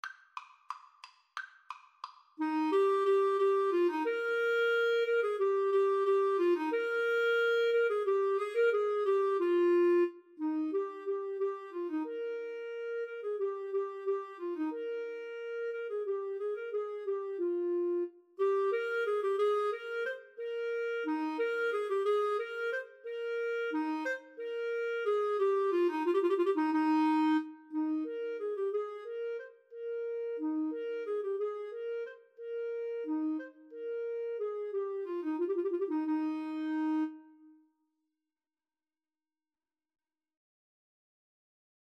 =180 Vivace (View more music marked Vivace)
Eb major (Sounding Pitch) (View more Eb major Music for Clarinet-Tenor Saxophone )
4/4 (View more 4/4 Music)
Classical (View more Classical Clarinet-Tenor Saxophone Music)